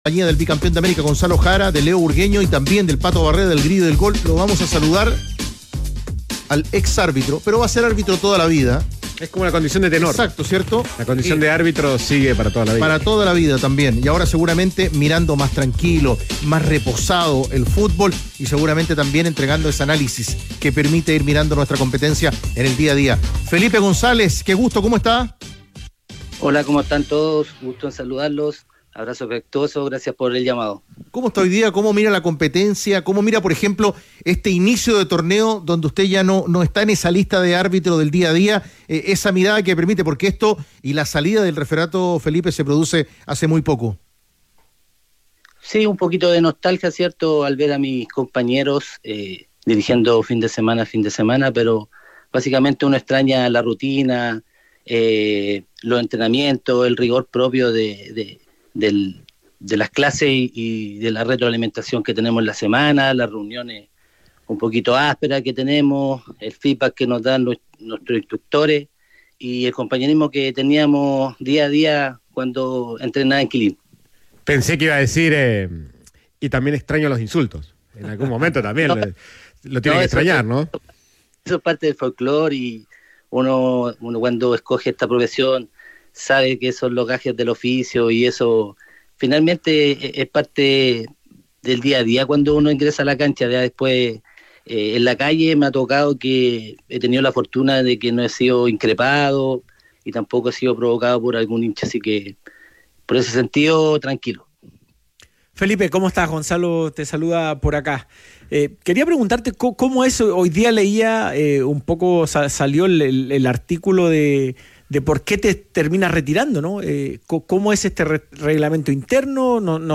En conversación con Los Tenores de la Tarde